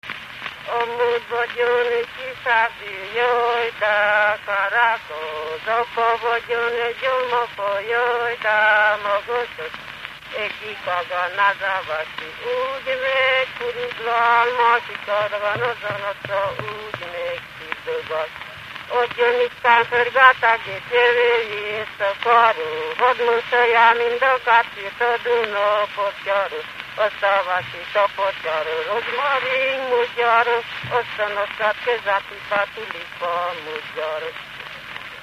Dunántúl - Zala vm. - Hottó
Dallamtípus: Gyermekdalok kordé-motívumokból
Stílus: 6. Duda-kanász mulattató stílus